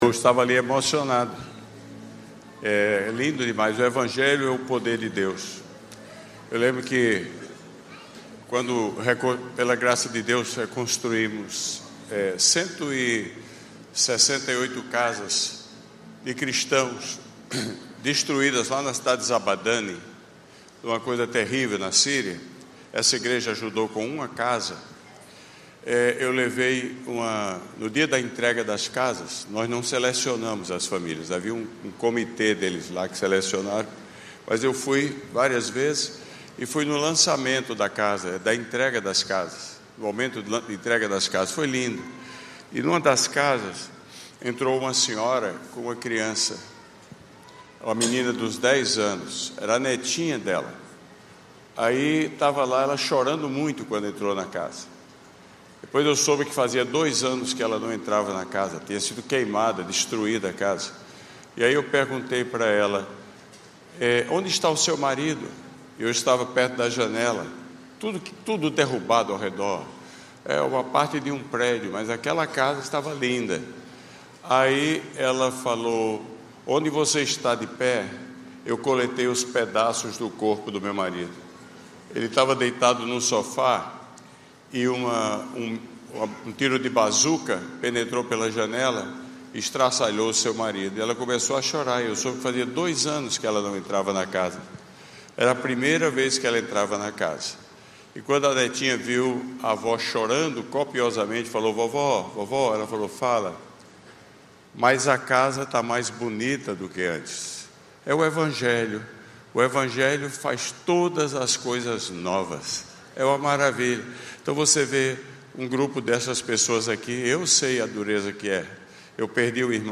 Igreja Batista do Recreio